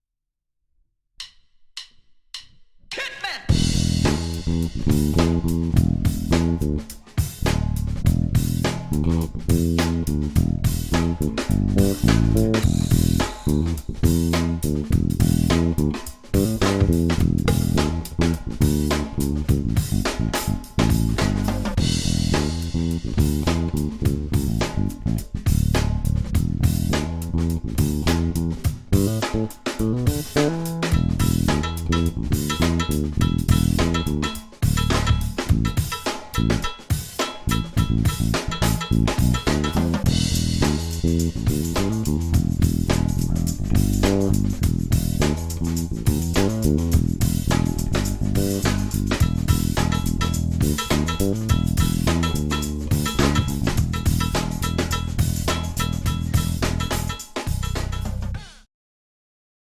MusicMan like zvuk - MM zvuk nevyhledávám, dá se to asi nakroutit lépe.